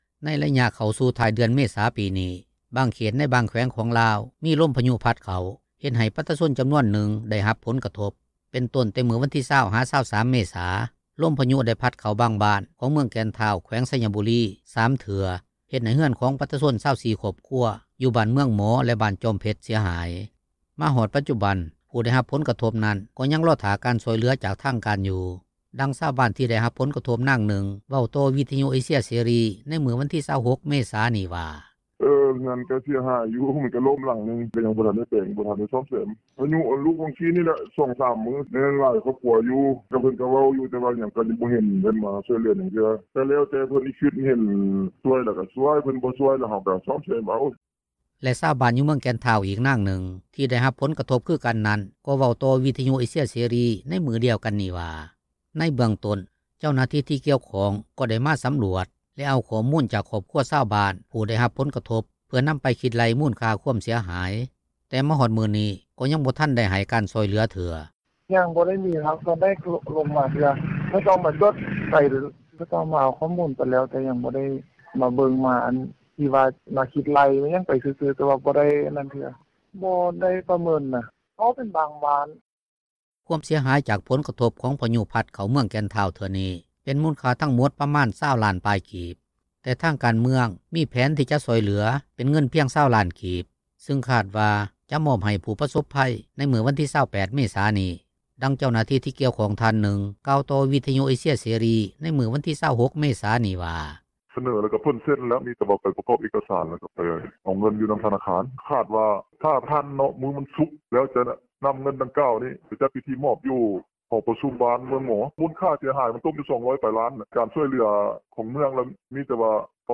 ດັ່ງຊາວບ້ານ ທີ່ໄດ້ຮັບຜົລກະທົບ ນາງນຶ່ງເວົ້າຕໍ່ ວິທຍຸເອເຊັຽ ເສຣີ ໃນມື້ວັນທີ 26 ເມສານີ້ວ່າ:
ດັ່ງເຈົ້າໜ້າທີ່ ທີ່ກ່ຽວຂ້ອງ ທ່ານນຶ່ງກ່າວຕໍ່ວິທຍຸ ເອເຊັຽເສຣີ ໃນມື້ວັນທີ 26 ເມສານີ້ວ່າ: